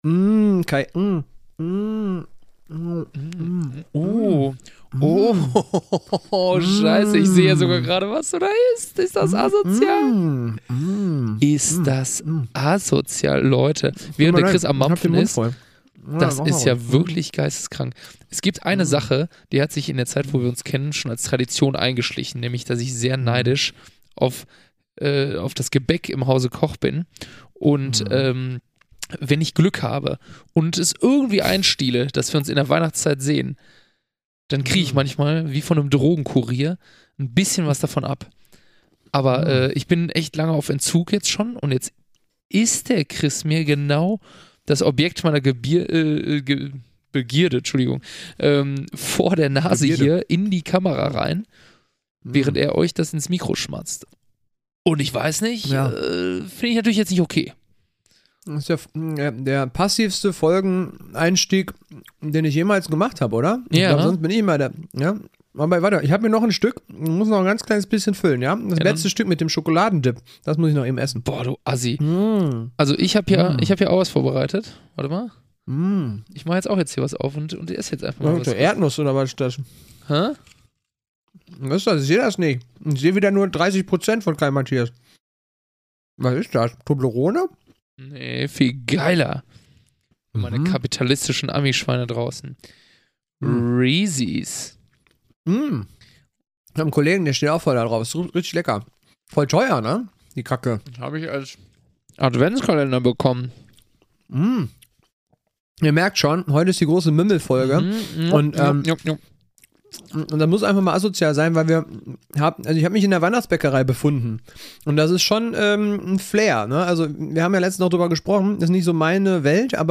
Hm… mampf, mümmel…In dieser Folge wird geschmatzt, gemeckert und gelacht